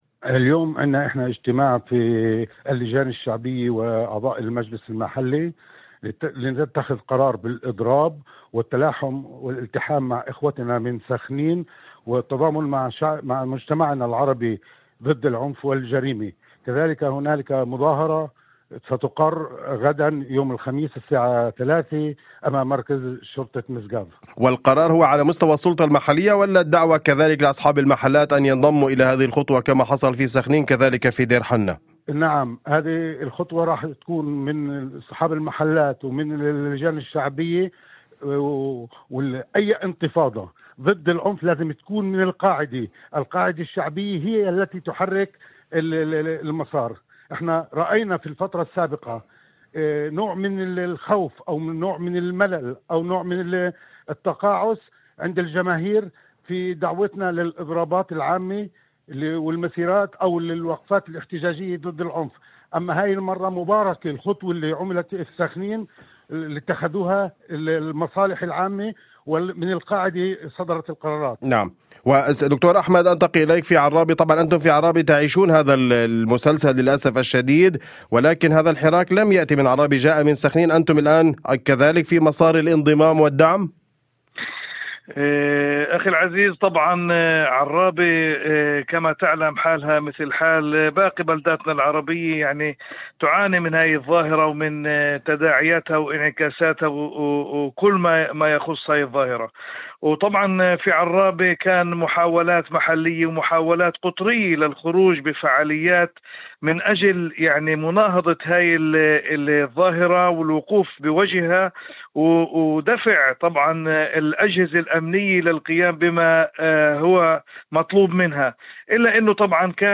وفي مداخلة هاتفية ضمن برنامج أول خبر على إذاعة الشمس، قال سعيد حسين، رئيس مجلس دير حنا المحلي، إن القرار جاء بعد اجتماع مشترك عقد اليوم بين اللجان الشعبية وأعضاء المجلس المحلي، وتم خلاله اتخاذ قرار بالإضراب والتلاحم مع بلدات أخرى، وعلى رأسها سخنين، في مواجهة ما وصفه بحالة العنف والجريمة التي تضرب المجتمع العربي.